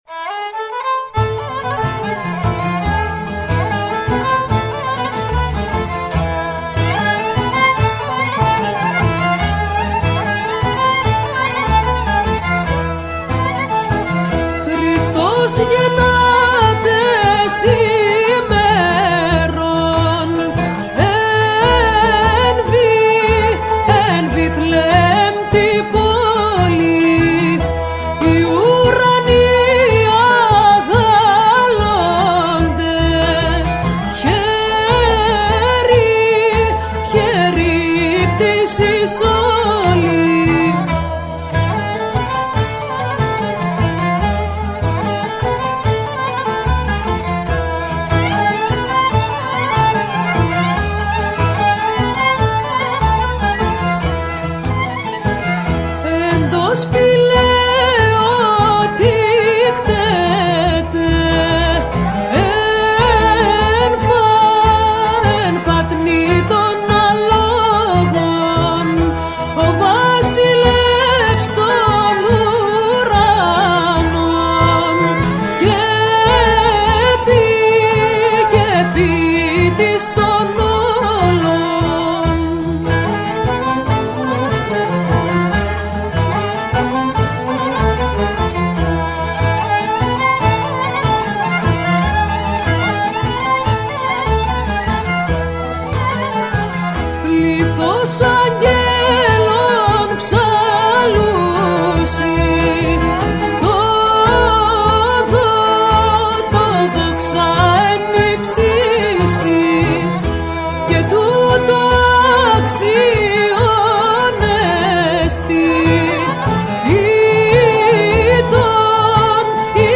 κάλαντα πάνω σε Σμυρνέϊκο μπάλλο